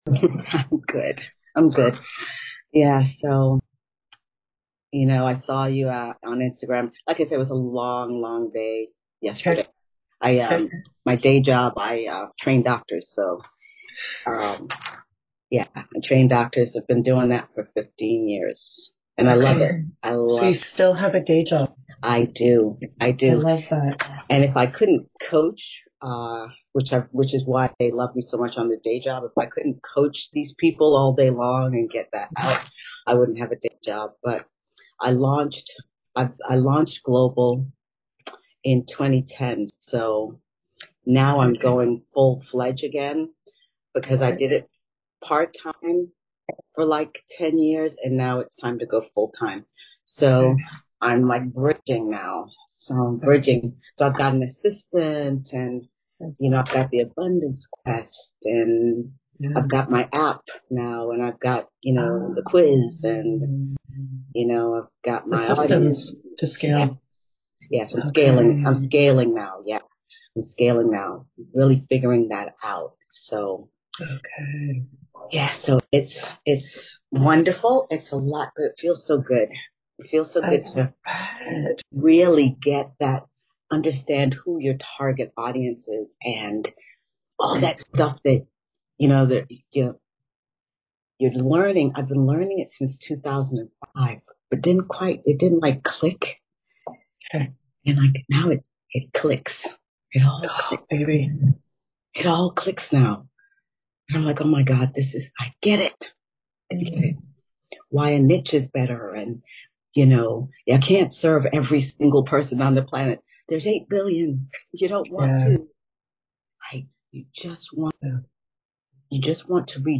disclaimer we got going so fast I didn't even record the intro, so hang on close & tight and lets gooooo!!!!